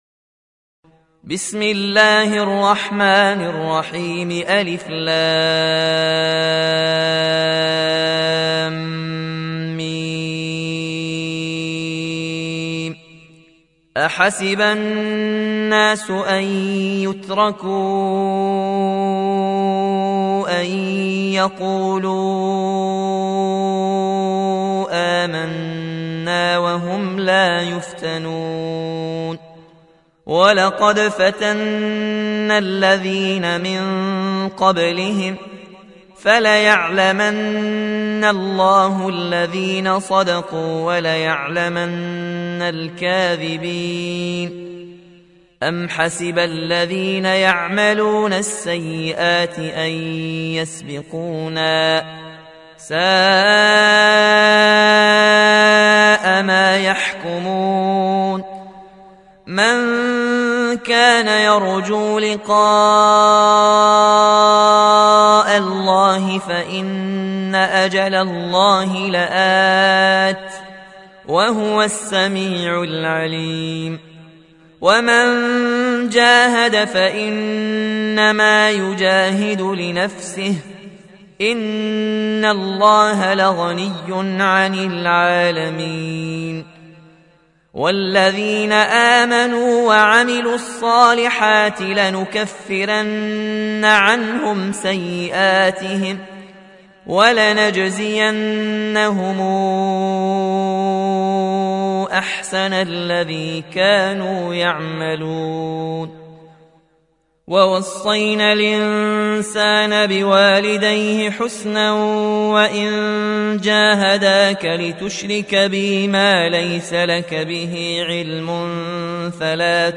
روایت ورش از نافع